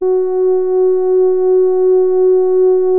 MOOG SOFT#F3.wav